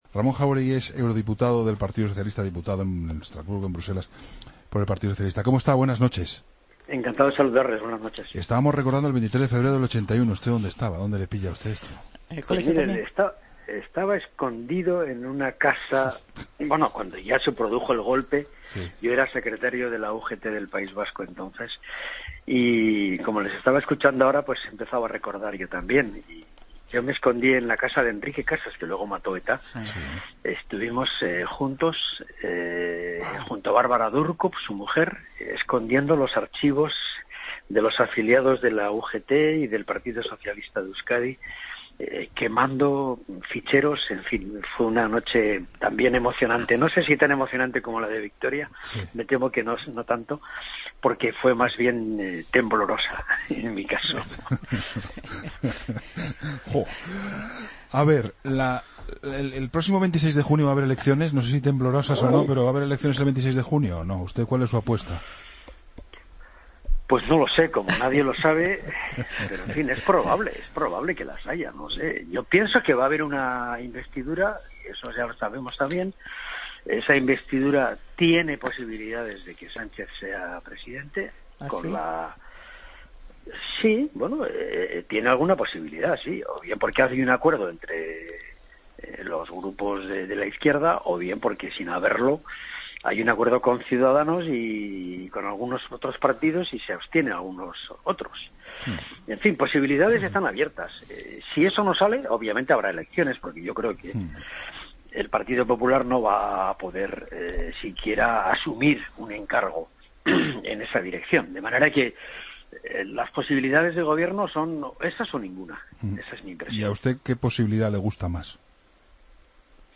Escucha la entrevista a Ramón Jáuregui, eurodiputado socialista.